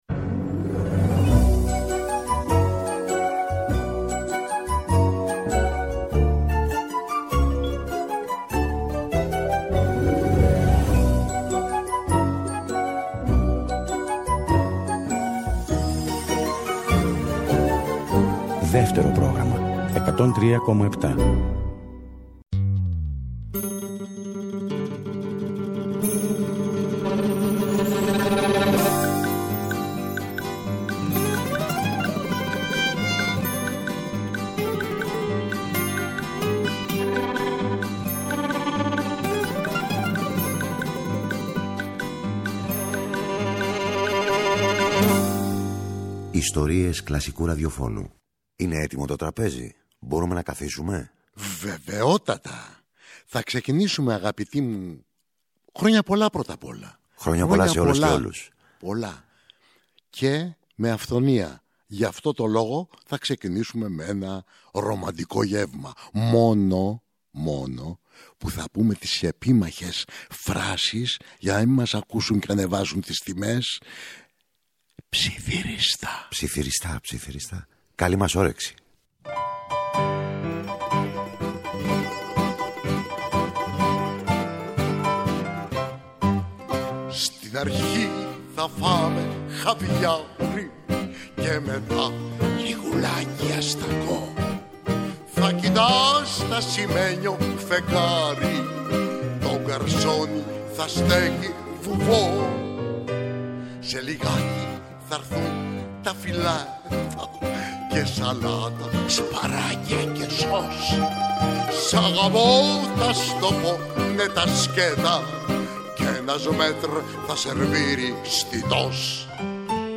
πιάνο και τραγούδι
μπουζούκι – μαντολίνο
κιθάρα